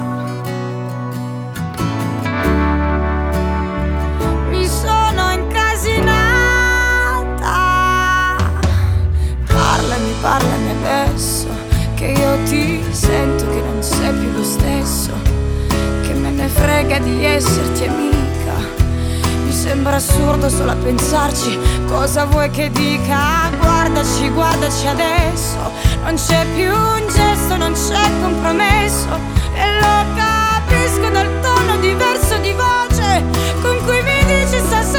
Жанр: Музыка мира / Русские